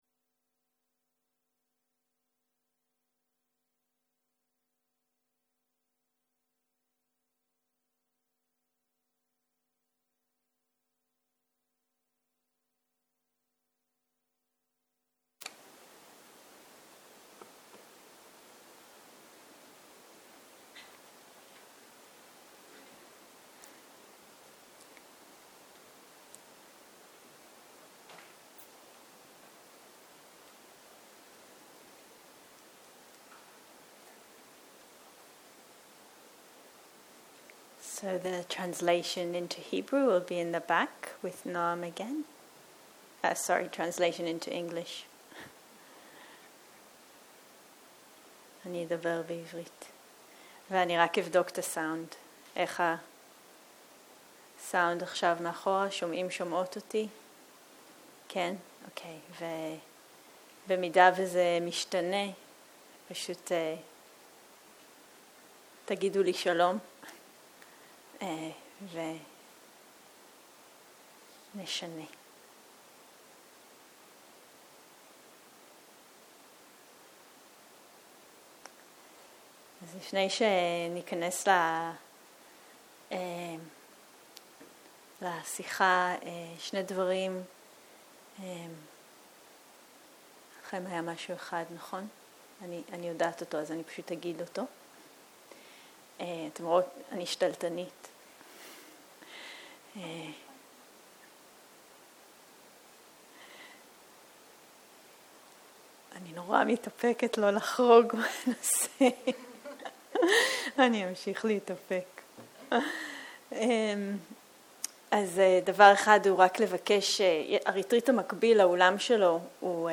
ערב - שיחת דהרמה - מטא וריקות - שיחת פתיחה